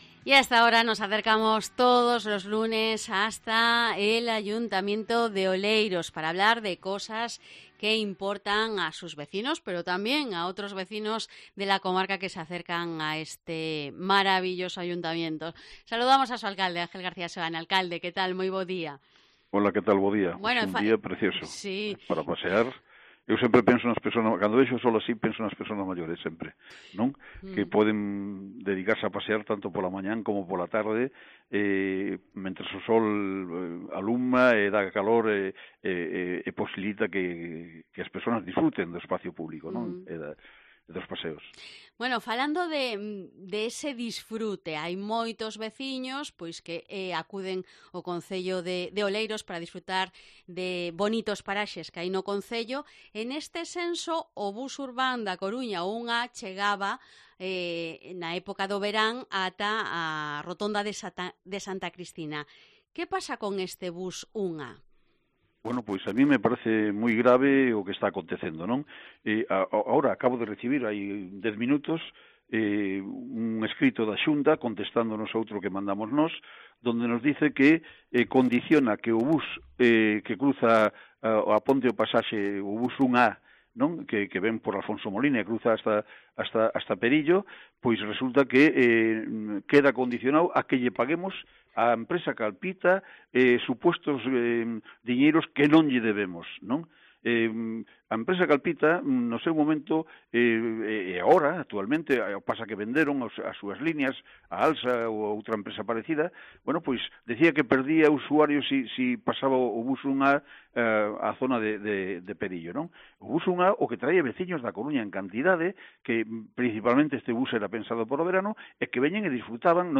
Entrevista al Alcalde de Oleiros, Ángel García Seoane